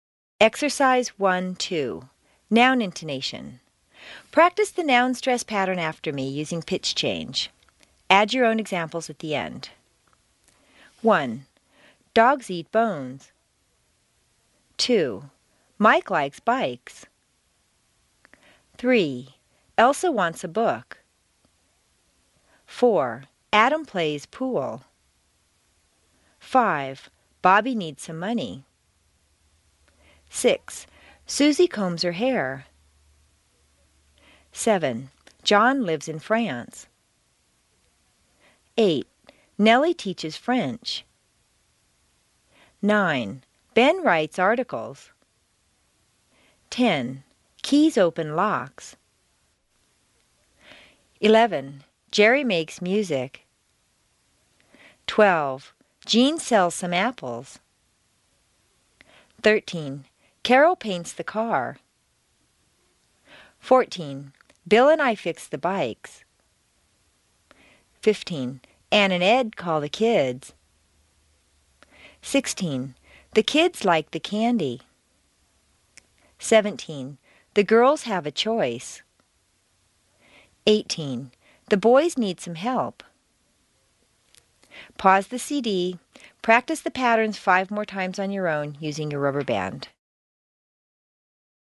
Practice the noun stress pattern after me, using pitch change.